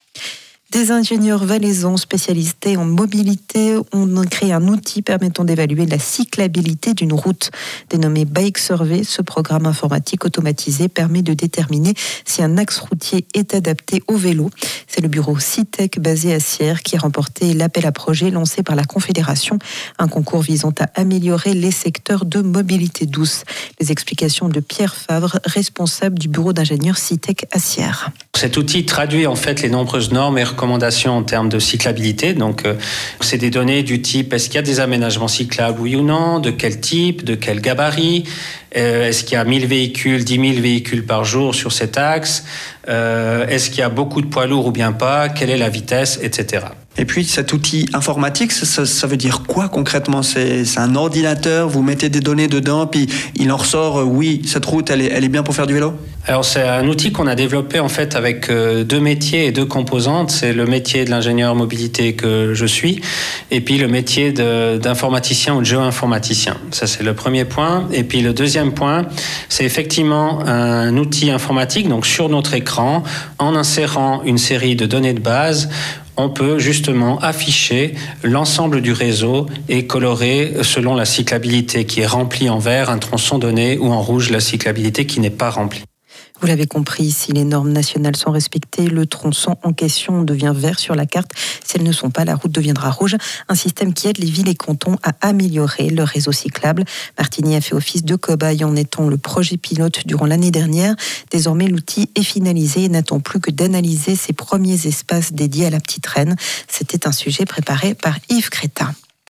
INTERVIEW – Sierre : Ils créent un outil qui permet d'évaluer si une route est adaptée aux vélos ou non.
Diffusé le 11 mars 2023 sur Rhône FM